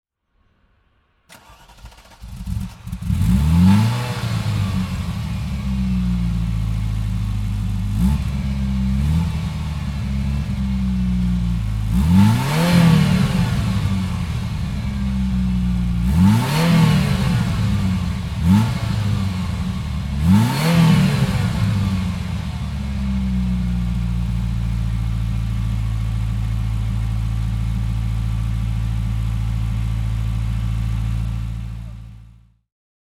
Ferrari 328 GTB (1986) - the fuel-injected eight-cylinder engineFerrari 328 GTB (1986) - the fuel-injected eight-cylinder engine
Ferrari 328 GTS (1988) - Starten und Leerlauf